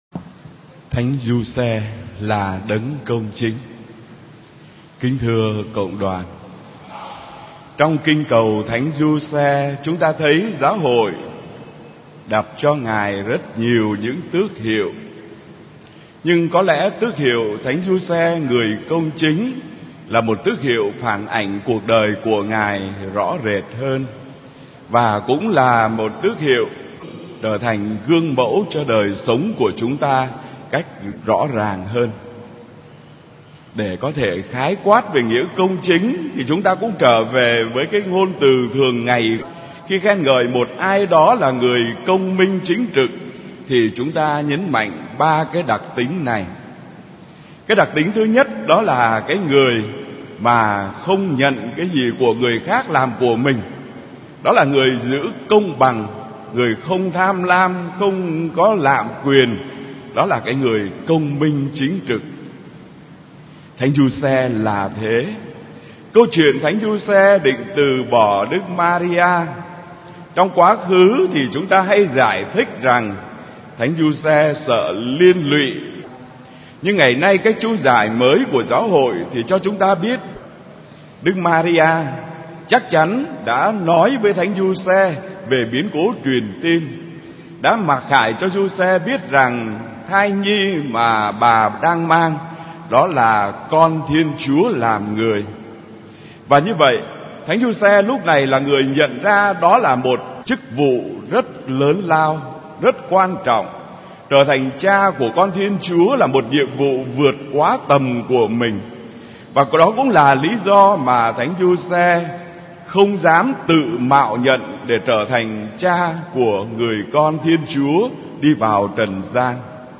Dòng nhạc : Nghe giảng